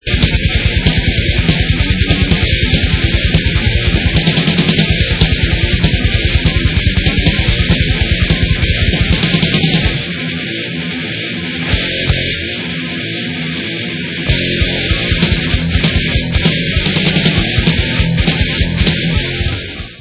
sledovat novinky v oddělení Rock - Speed/Thrash/Death Metal